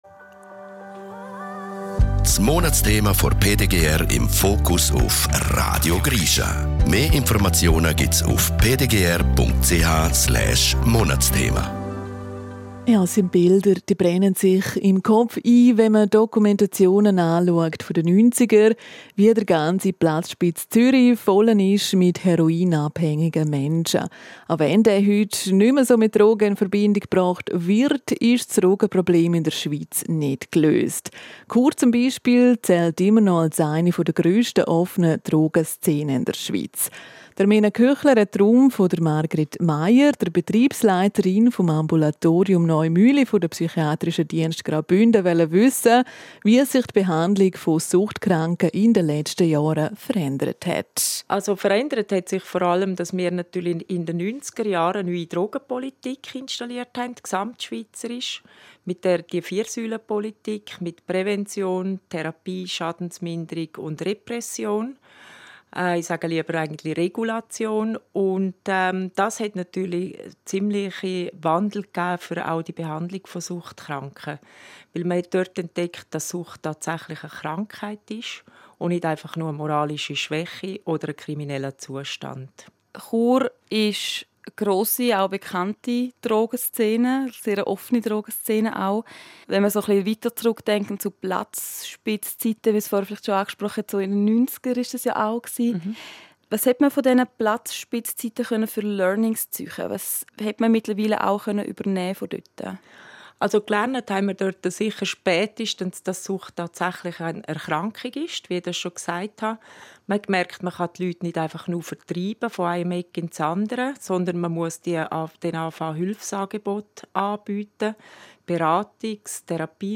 Radiobeiträge